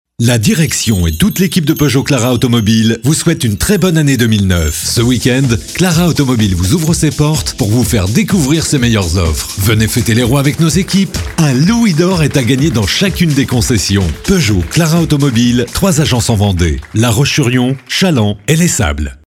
Voix masculine
Voix Graves